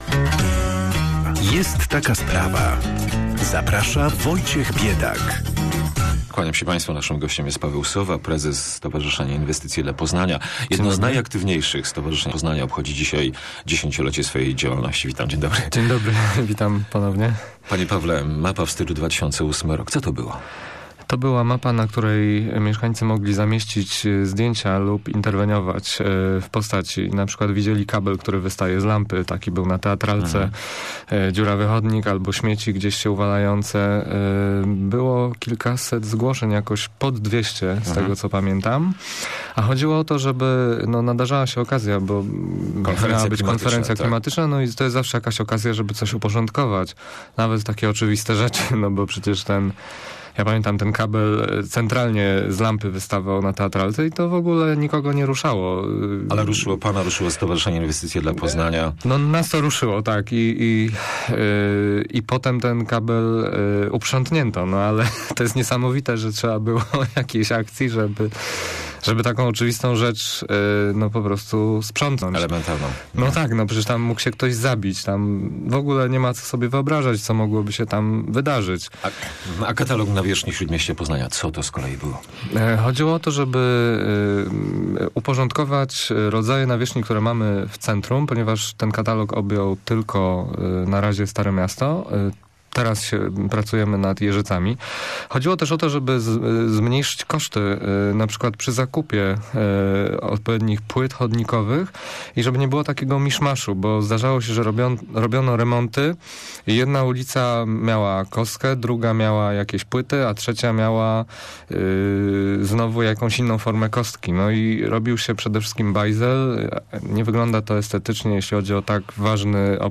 (cała rozmowa poniżej)